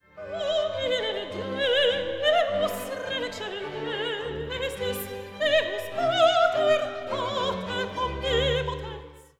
The pace continues in brisk and bracing duple-time as Mozart crams the entire Gloria into a space only about thirty seconds longer than the Kyrie.  There are no pauses and the pace never slows.  No puzzles here; the performers must sing fast and articulate clearly against a frenetic and insistent orchestral background.
Domine Deus“, soloists; “
After a couple of lovely solo passages to start “Domine Deus”, there is a sudden flurry of dense Type II singing by the solo quartet.